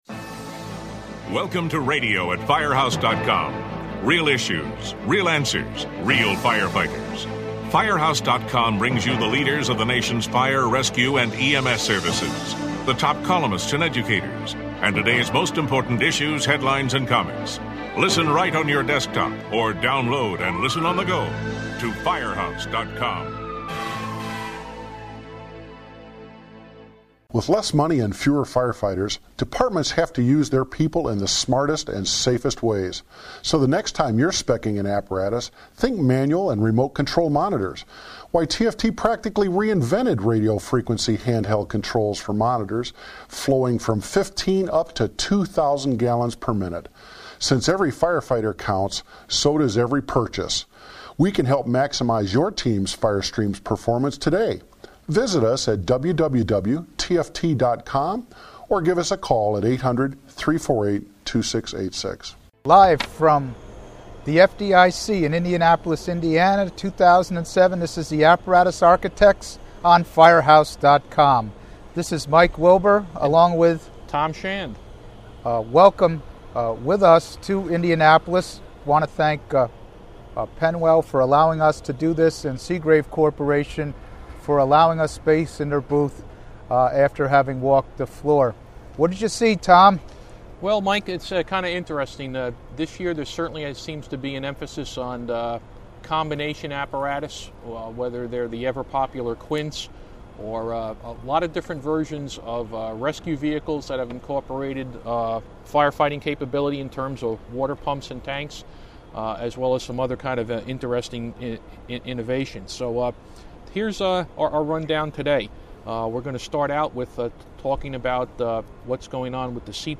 The Apparatus Architects: Live from FDIC
The Apparatus Architects come to you from the show floor at FDIC where several new models of apparatus were unveiled.